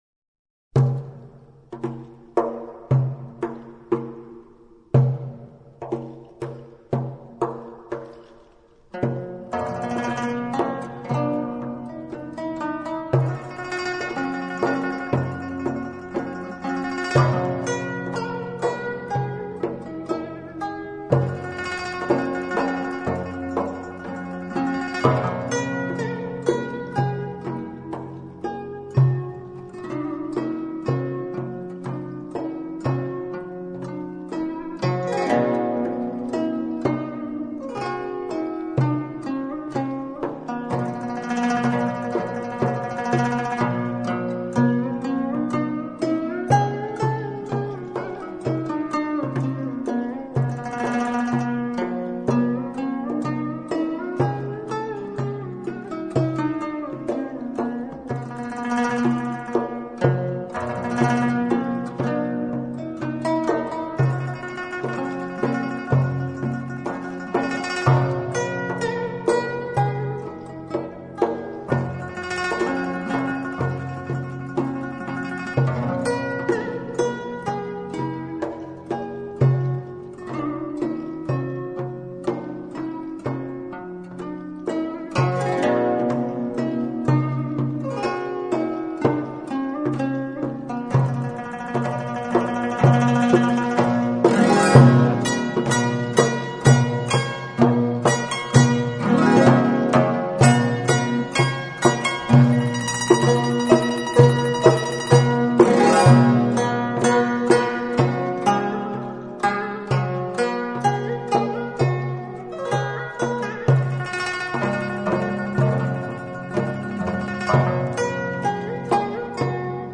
追求极致的完美录音，震撼发烧友的中国音乐
在百年老教堂的绝佳自然音场中
绝佳的演奏功力、音场和录音